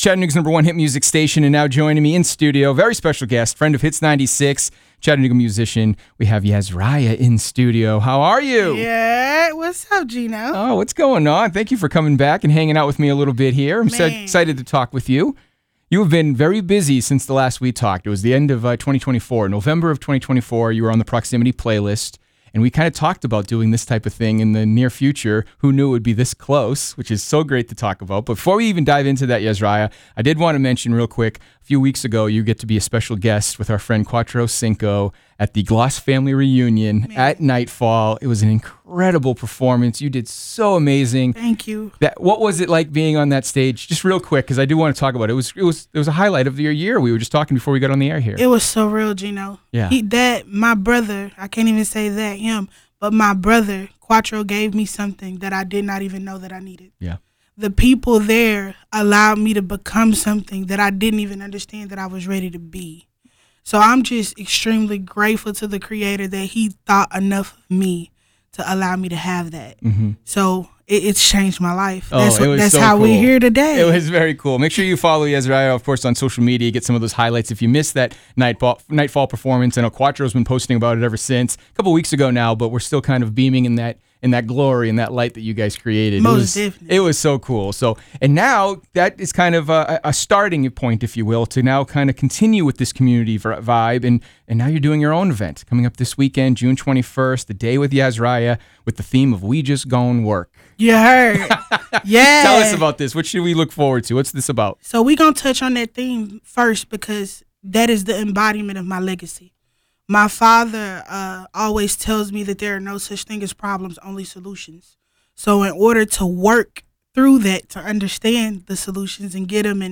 Full-Interview.wav